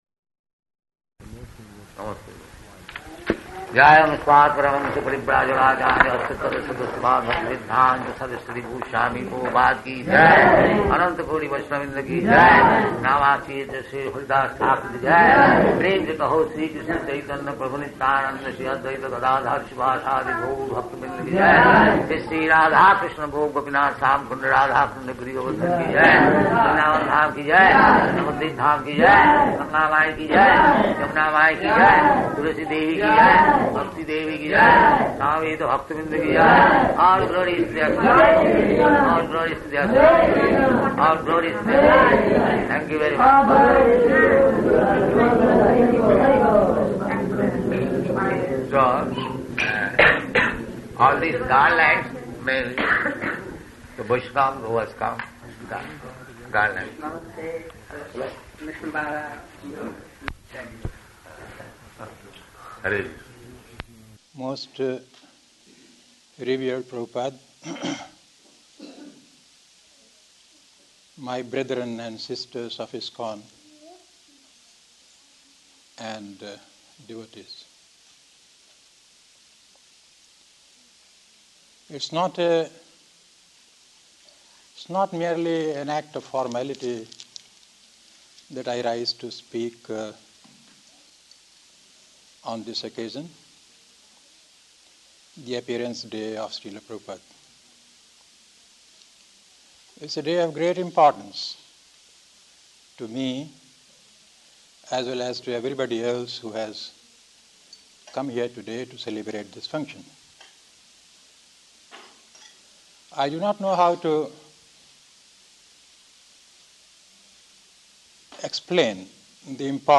Location: Vṛndāvana
[poor recording]